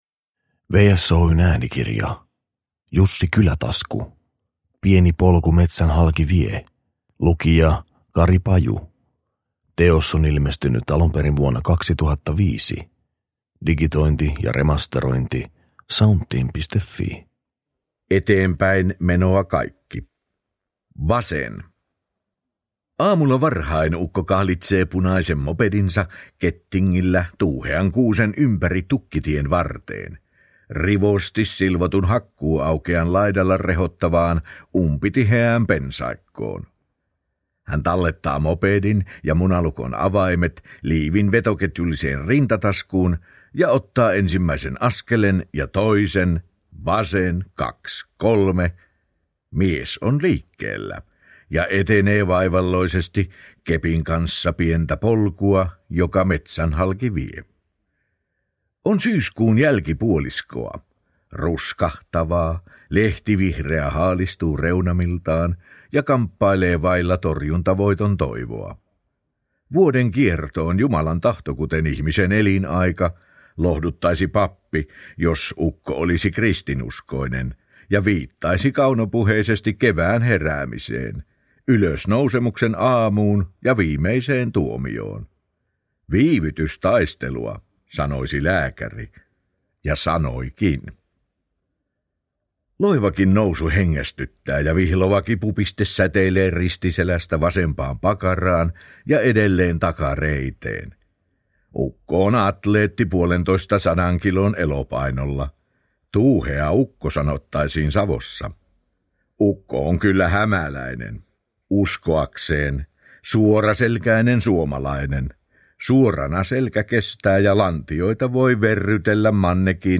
Pieni polku metsän halki vie – Ljudbok